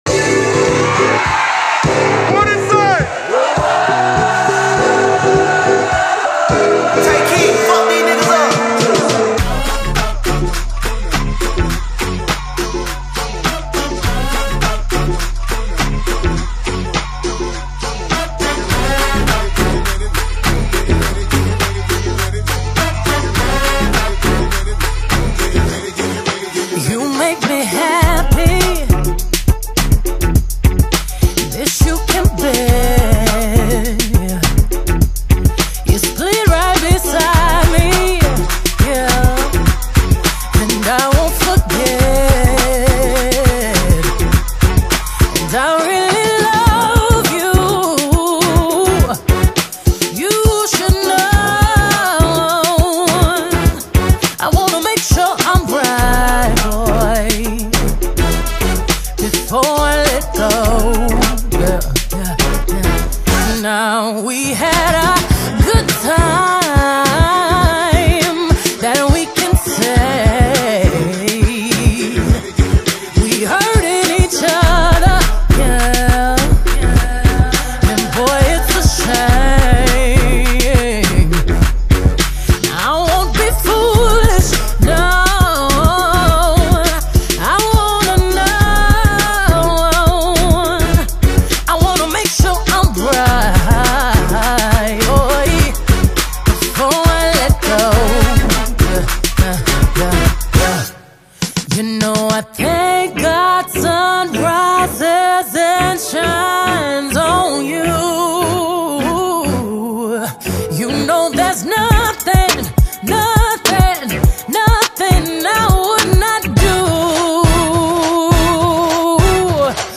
off her live album